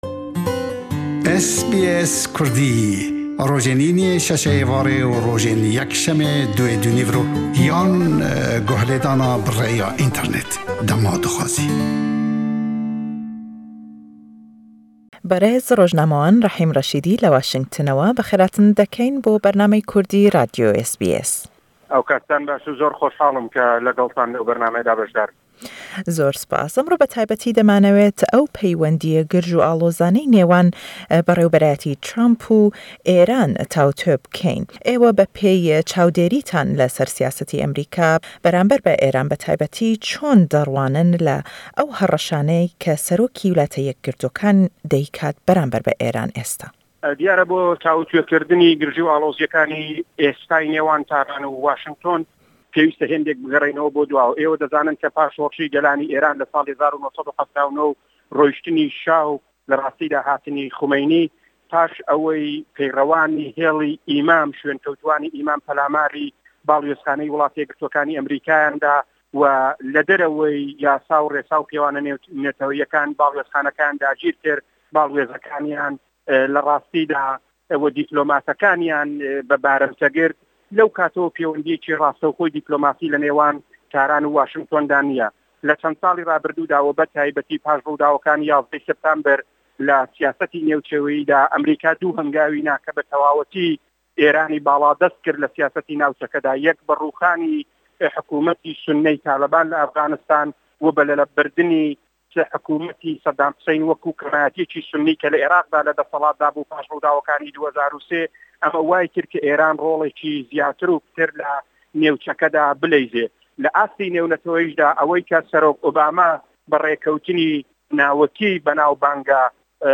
Le em lêdwaney xware ew peywendîye girjû allozaney nêwan Washington û Taran tawûtwê dekeîn